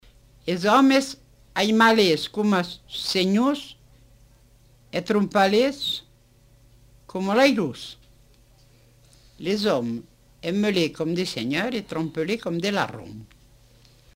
Aire culturelle : Comminges
Lieu : Montauban-de-Luchon
Effectif : 1
Type de voix : voix de femme
Production du son : récité
Classification : proverbe-dicton